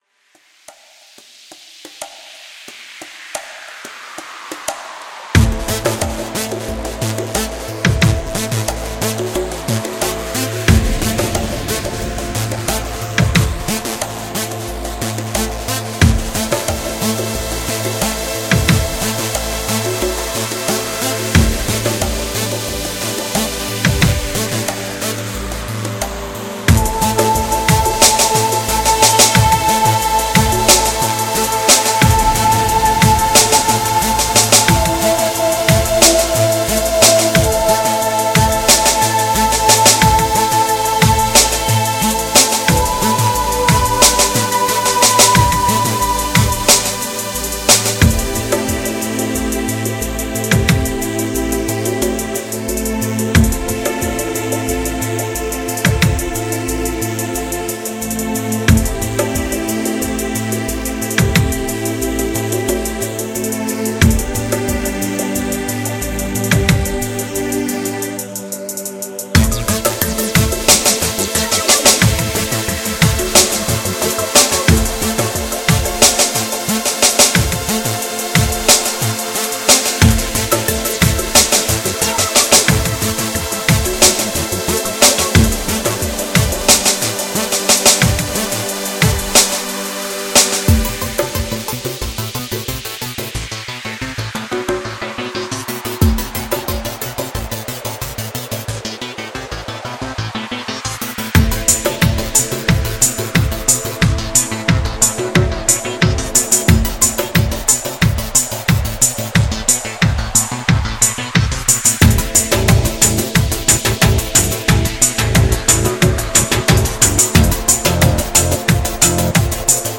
Ich mache hauptsächlich elektronische, instrumentale Musik.